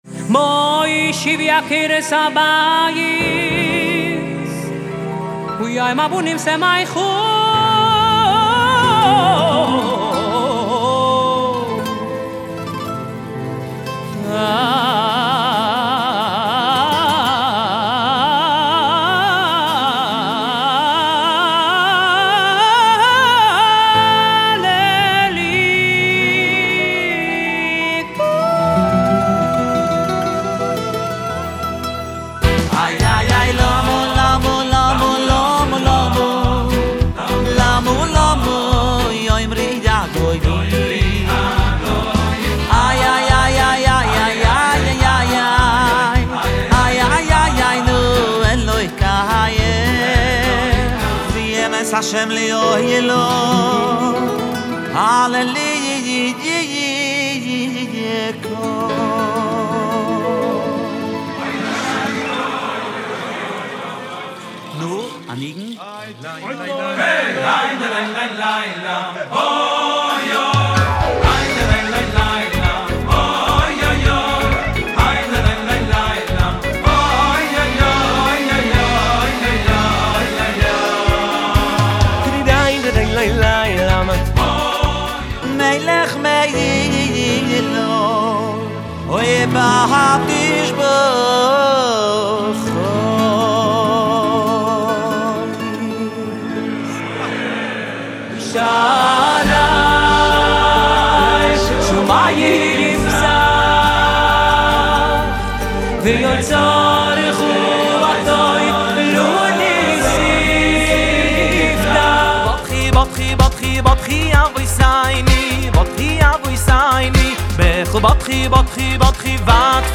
לחצו PLAY להאזנה למיקס משירי האלבום
ממש יפה אין כמו מוזיקה חסידית מקורית ללא זיופים